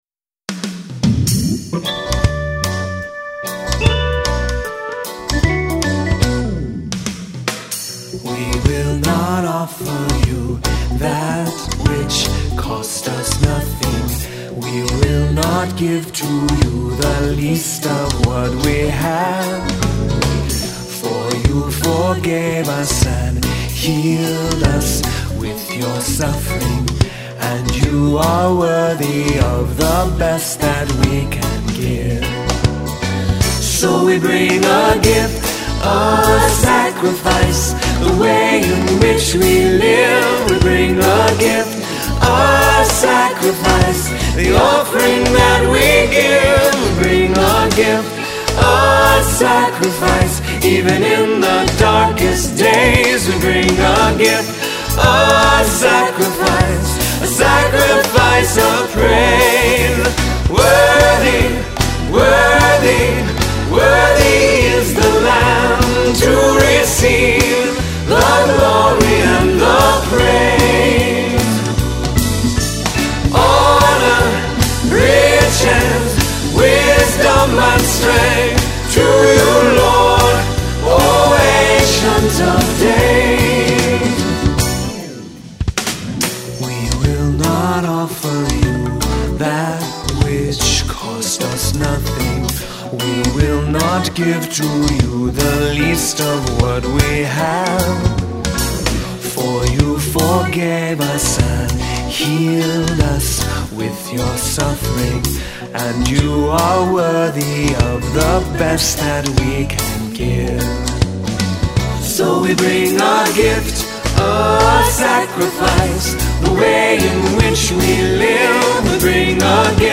a local group in Minnesota that is very diverse